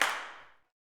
CLAPSUTC4.wav